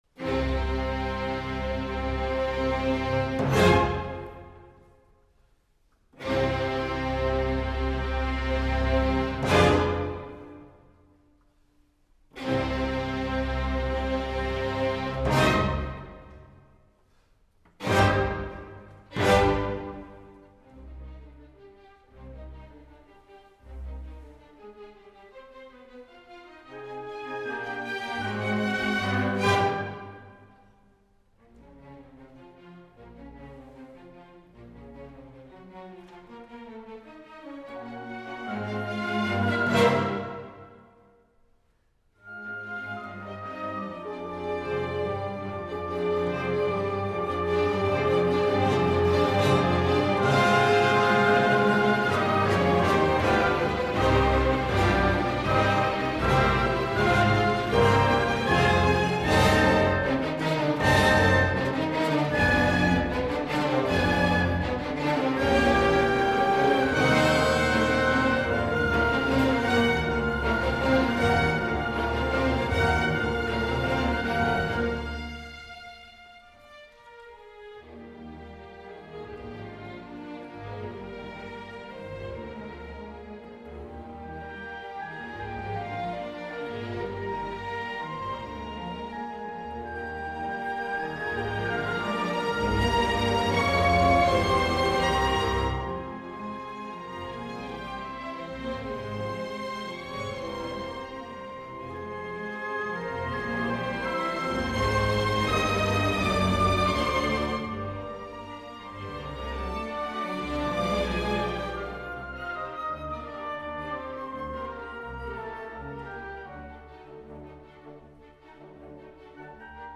Abbiamo scambiato alcune parole con Markus Poschner durante le prove per riflettere su questi dieci anni passati insieme all’OSI.